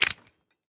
grenade_pull.ogg